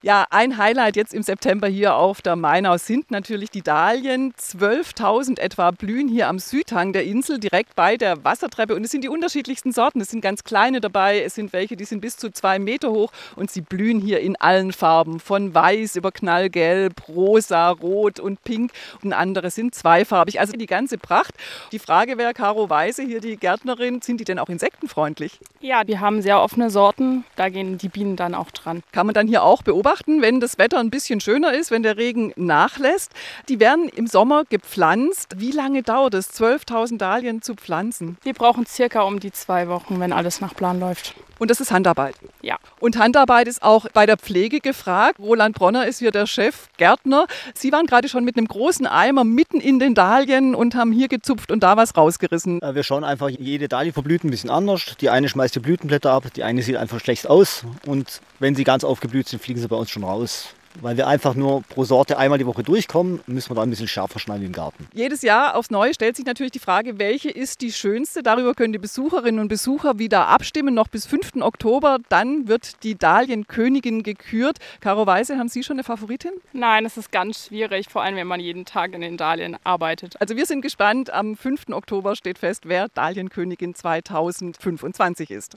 bei der Dahlienschau: